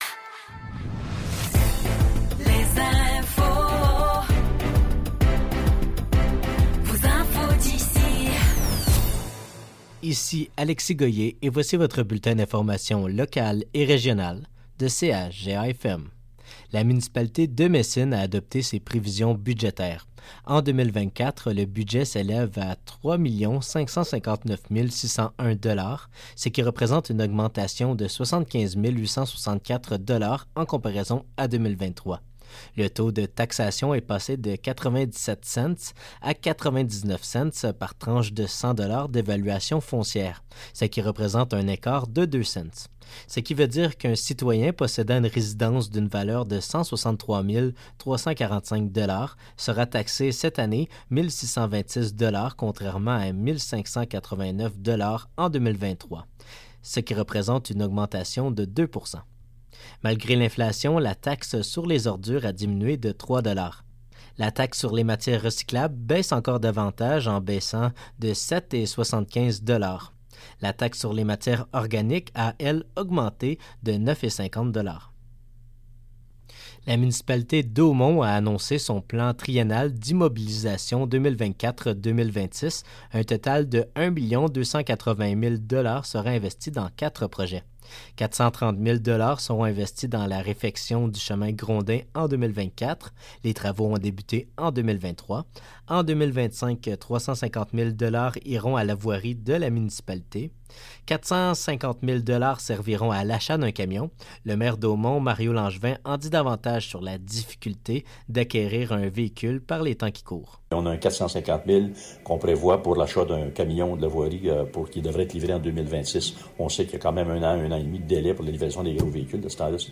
Nouvelles locales - 28 décembre 2023 - 16 h